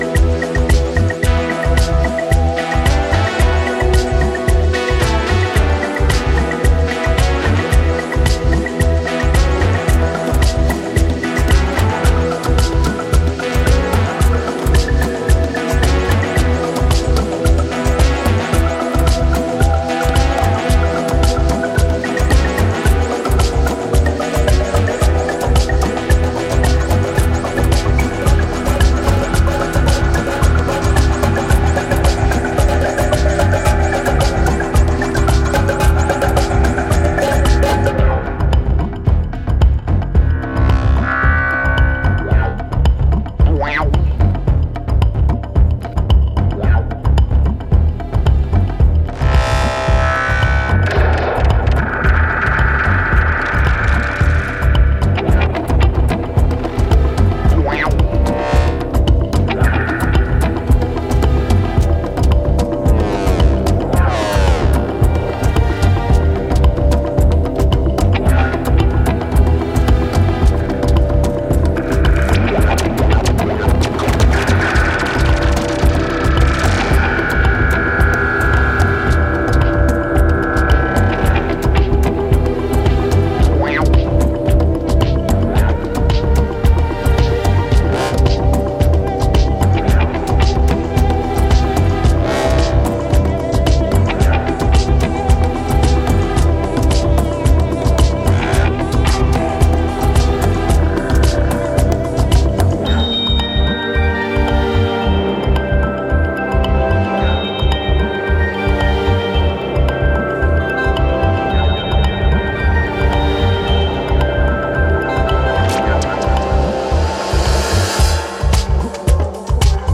コーラス等をミュートしたリズム・トラック・バージョン